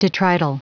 Prononciation du mot detrital en anglais (fichier audio)
Prononciation du mot : detrital